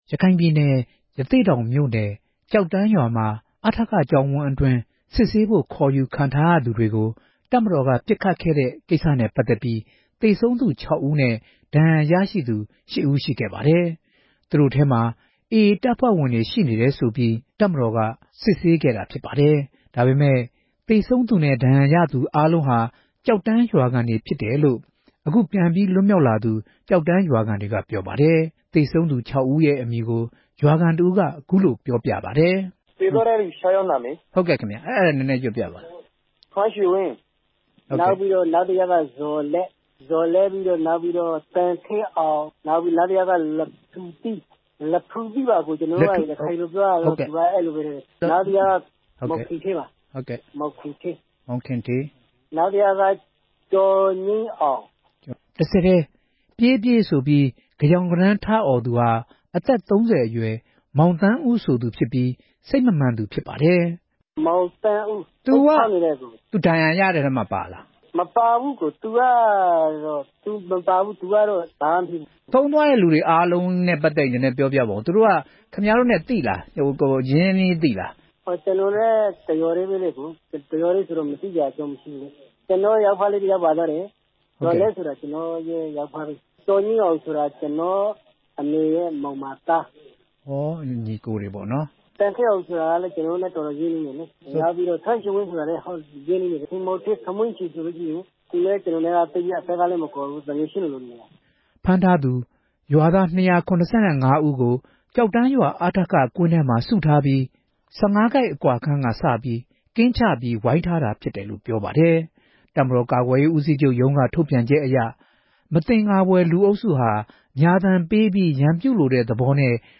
ဖမ်းဆီးခံနေရာက လွတ်မြောက်လာသူ ရွာသားတွေကို